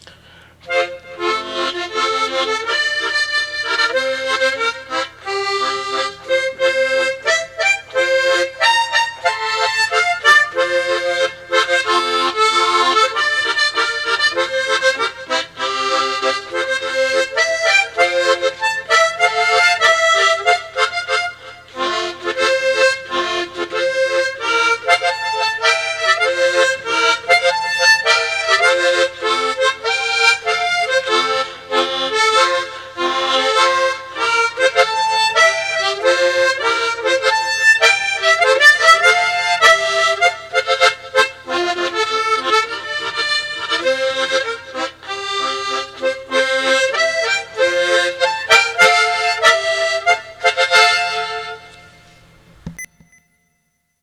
Tremolo